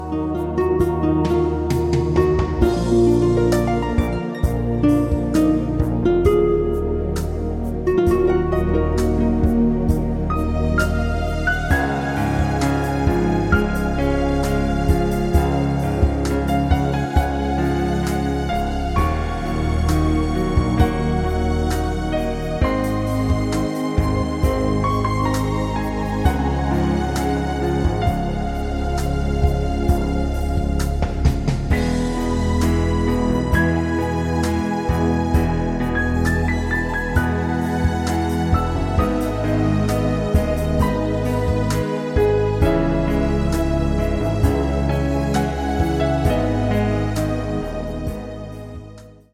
Awarded Best New Age Album in  May 2005.